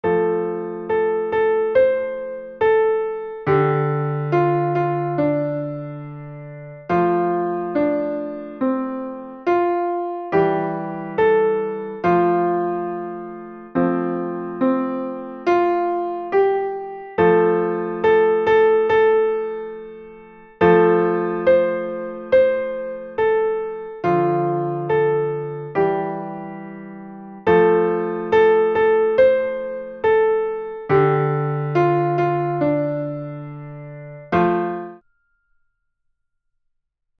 ★伴奏音源sample